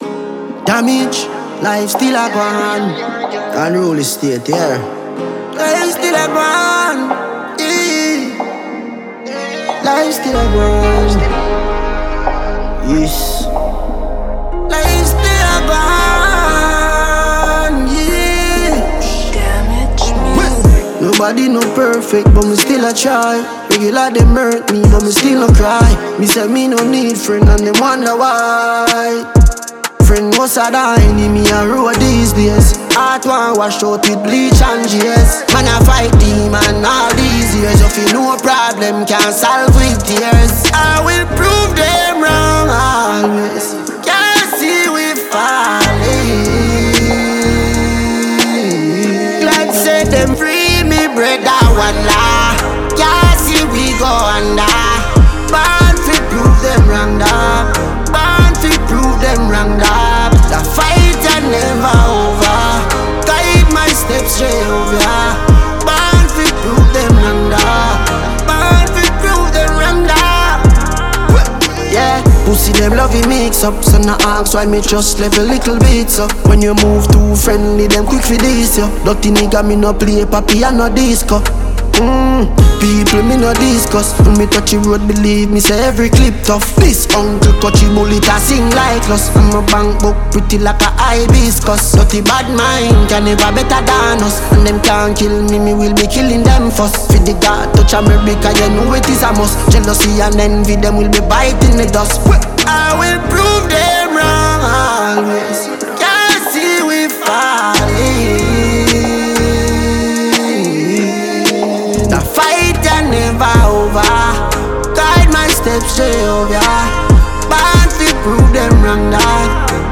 Jamaican dancehall
dancehall’s infectious rhythms